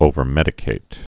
(ōvər-mĕdĭ-kāt)